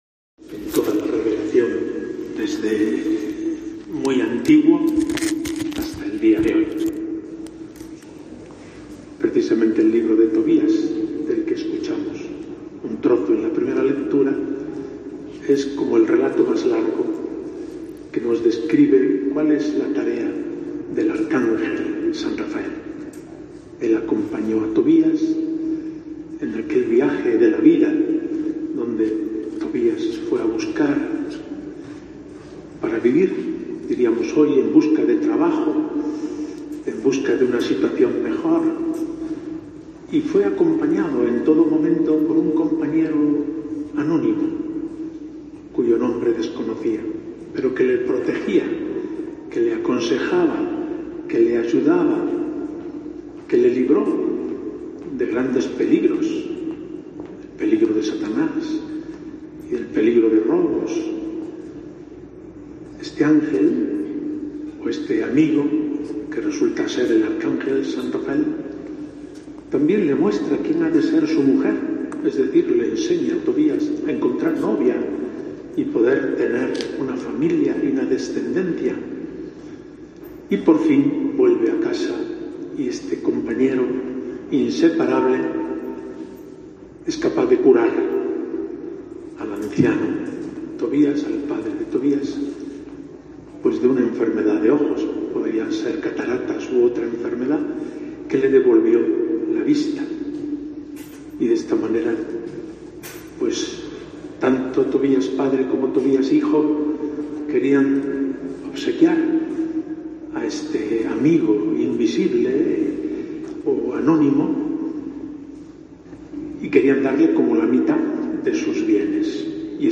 Como cada año, el obispo de Córdoba, Mons. Demetrio Fernández, ha oficiado la misa en la Iglesia del Juramento ante la imagen del Arcángel acompañado en esta ocasión por el nuevo arzobispo de Burgos, monseñor Mario Iceta, y miembros del Cabildo Catedral.